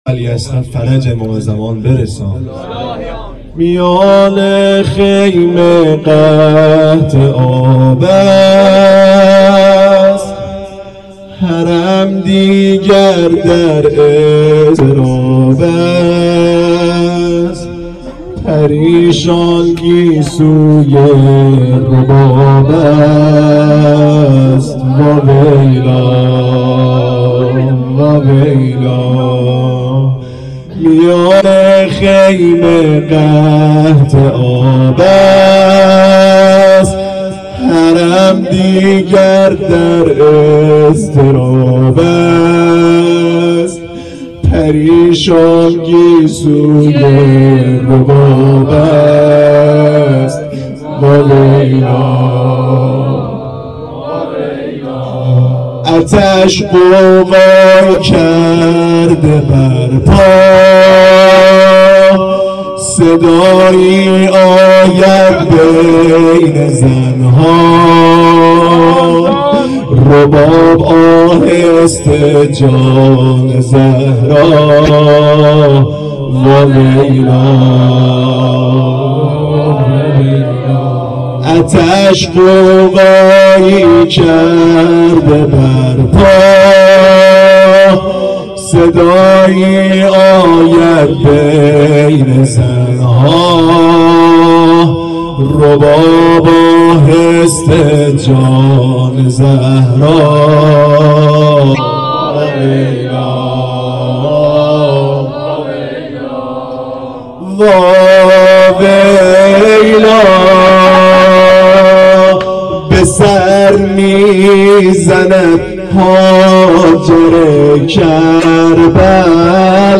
4-nohe.mp3